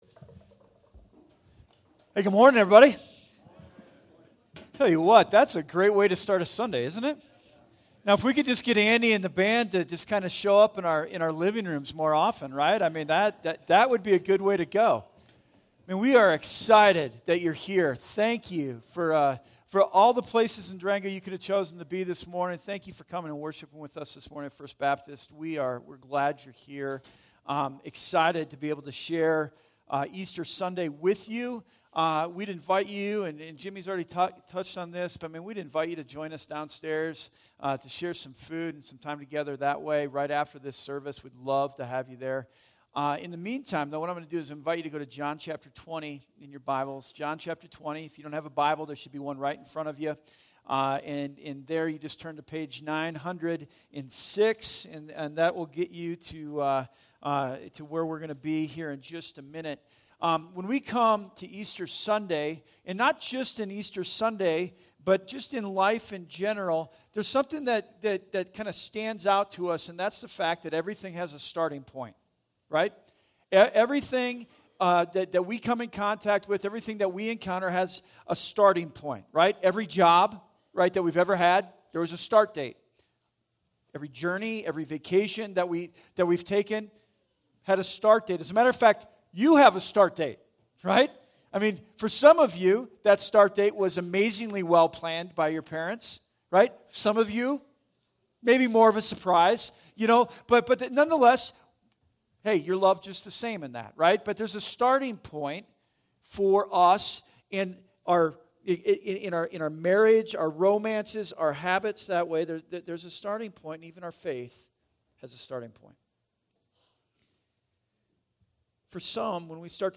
2019 It All Starts Here Preacher